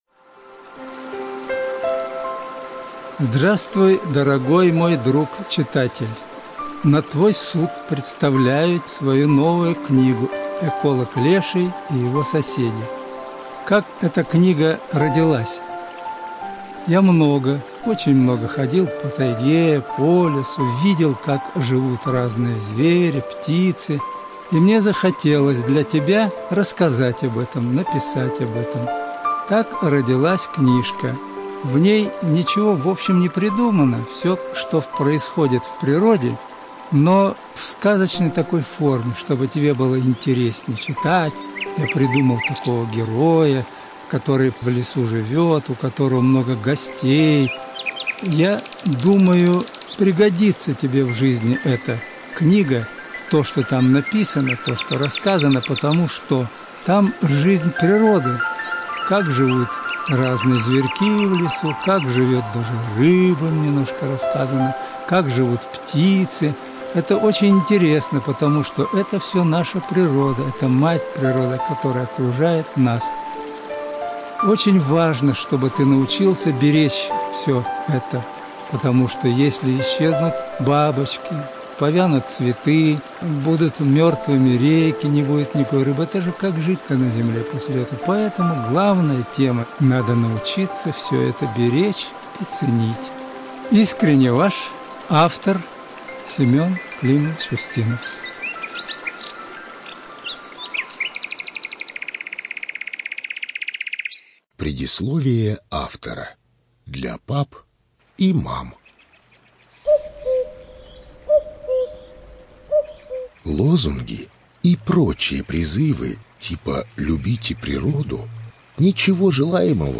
Студия звукозаписиИркутская областная библиотека для слепых